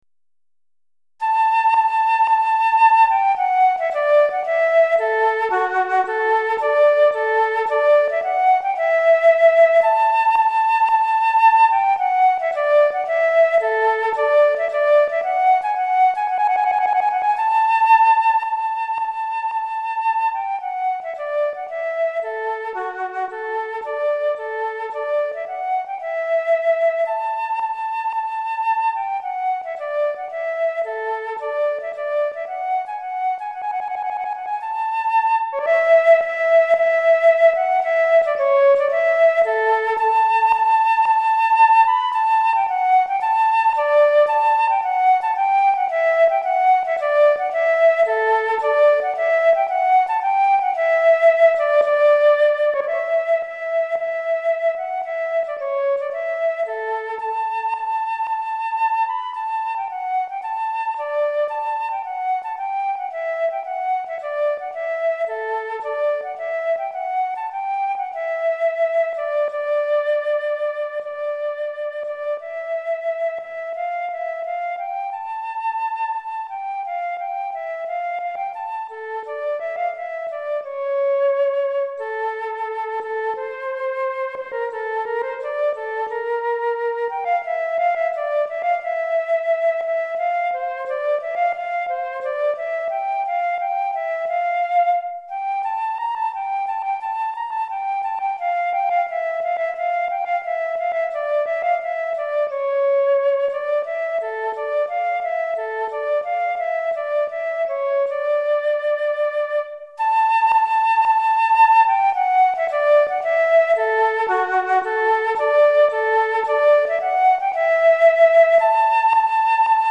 Flûte Traversière Solo